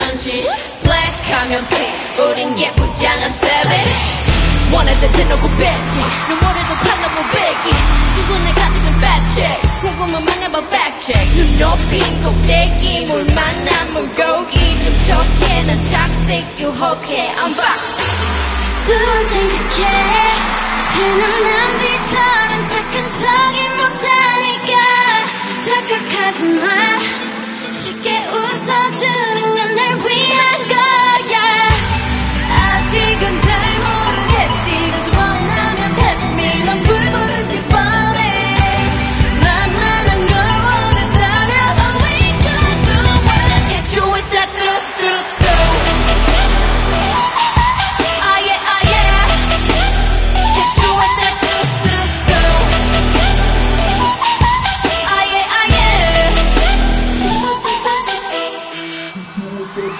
LIVE PERFORMANCE
kpop